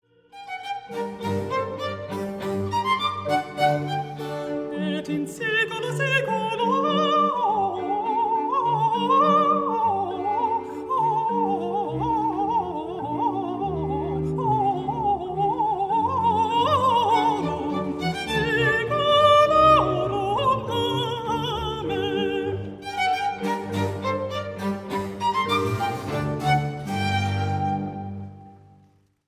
Alt Alt